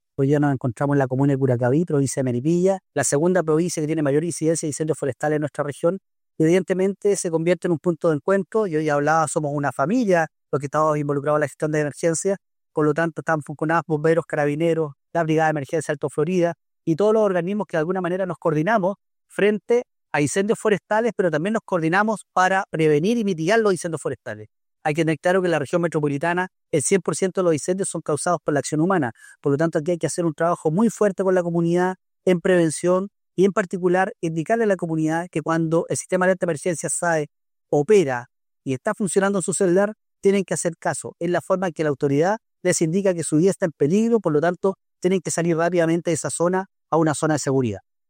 En el aeródromo de Curacaví se llevó a cabo el lanzamiento del Plan Regional de Acción de Prevención, Mitigación y Control de Incendios Forestales 2025-2026, actividad que contó con la presencia del delegado presidencial provincial de Melipilla, Bastián Alarcón; el director ejecutivo de CONAF, Rodrigo Illesca; la directora regional de CONAF Metropolitana, Elke Huss; y el director regional de SENAPRED, Miguel Muñoz, participando diversas autoridades, entre otros servicios públicos, privados y voluntariados que son parte de la emergencia.